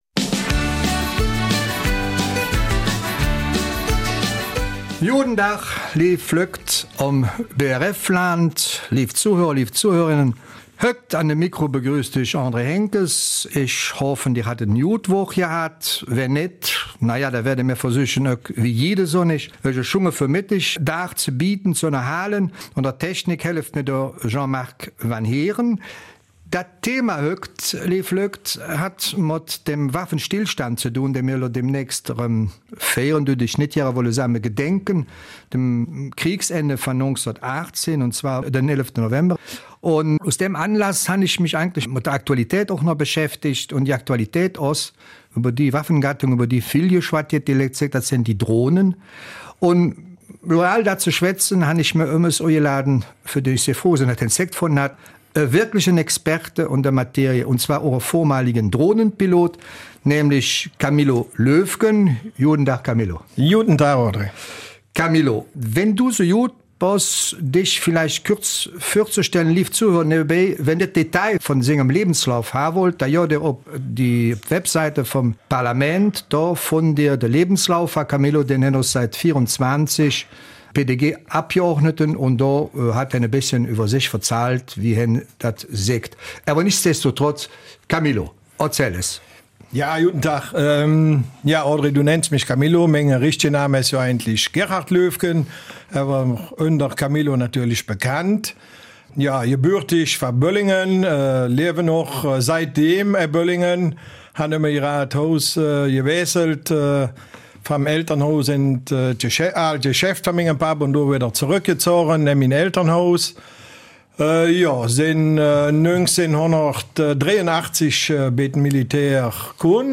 Eifeler Mundart: Die belgische Armee - Sinn oder Unsinn?